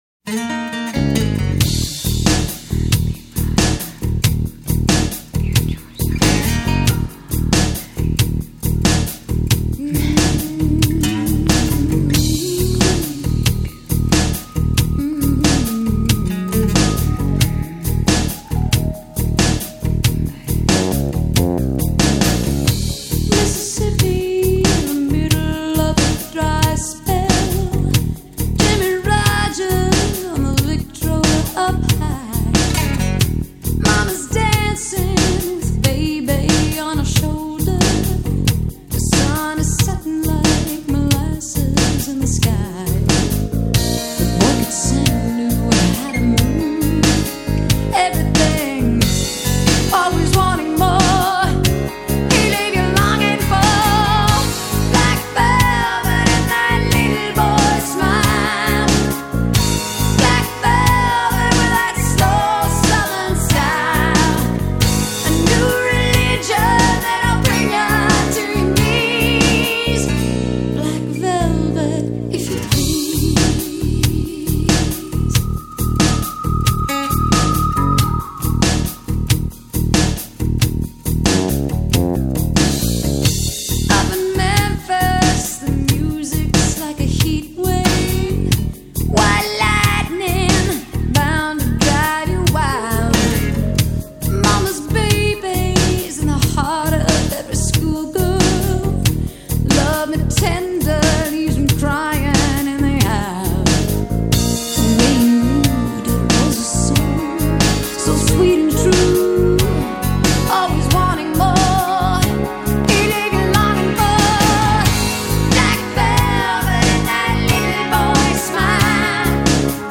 Жанр: hardrock